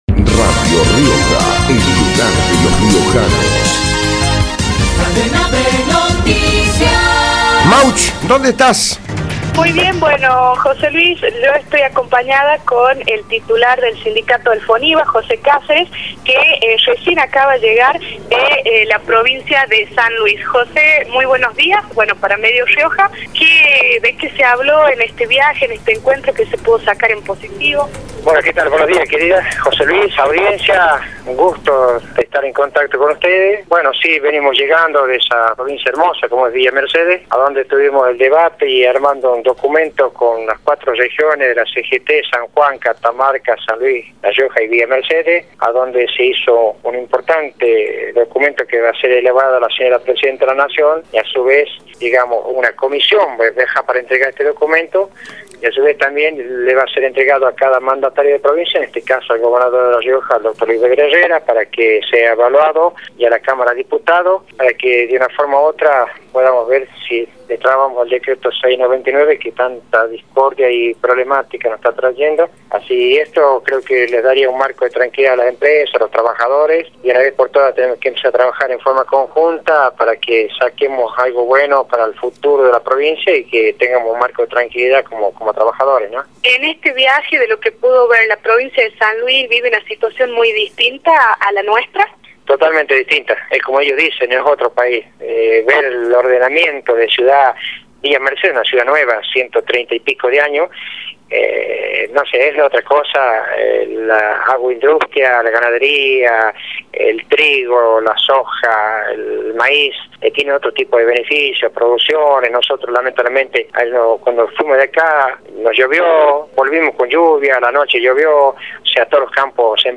por Radio Rioja